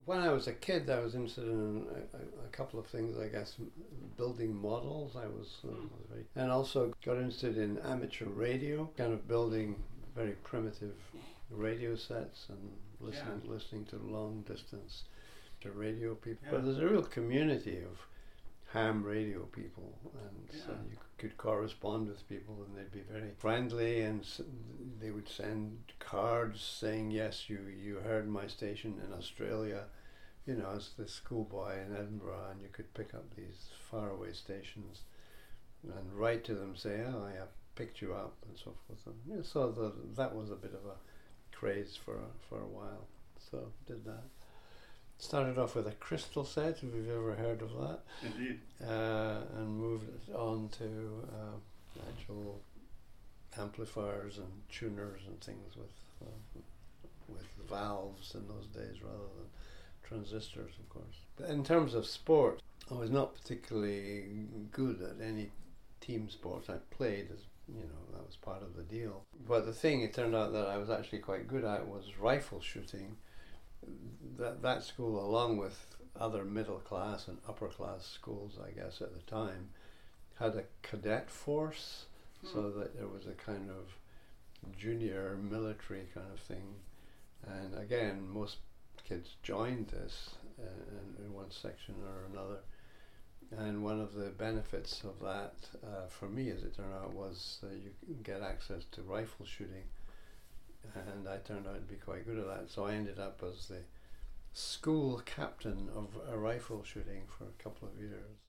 He describes some of those early activities in the following excerpt from our conversation:
au_schoolhobbies_craik.mp3